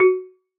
iron_xylophone.ogg - 1.21.4
iron_xylophone.ogg